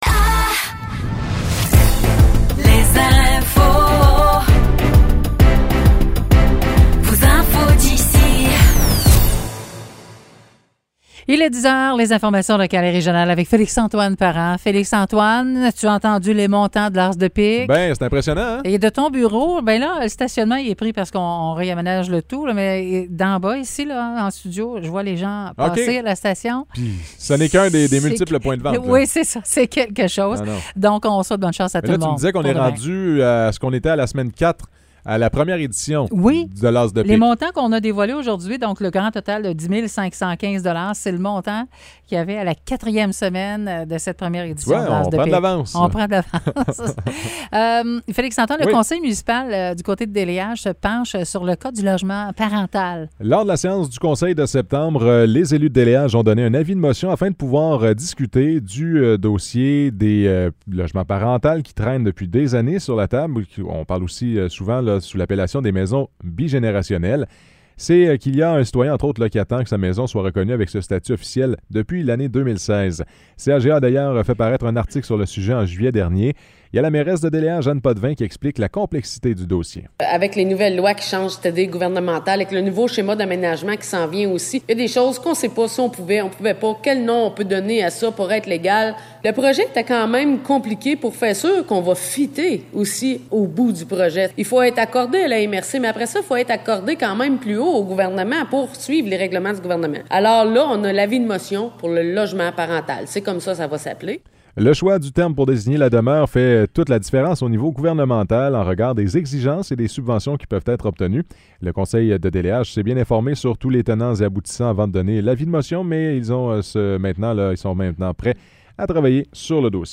Nouvelles locales - 13 septembre 2023 - 10 h